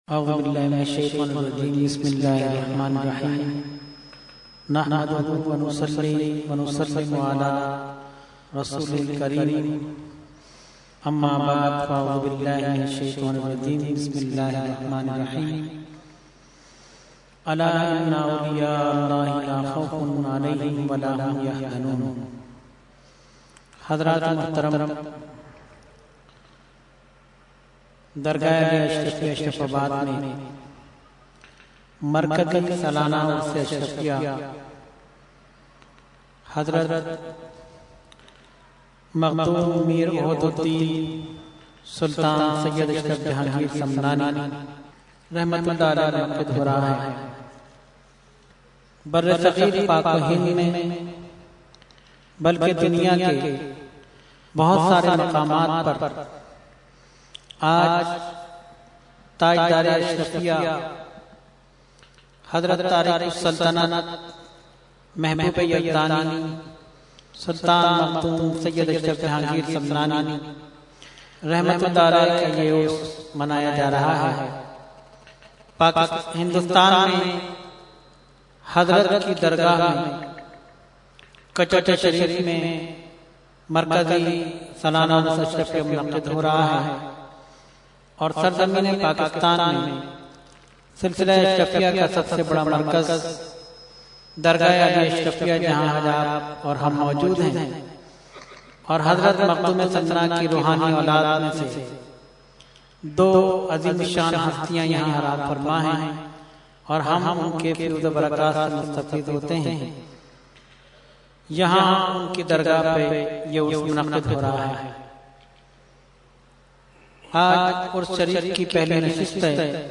Short Speech – Urs Makhdoome Samnani 2012 – Dargah Alia Ashrafia Karachi Pakistan
Category : Speech | Language : UrduEvent : Urs Makhdoome Samnani 2012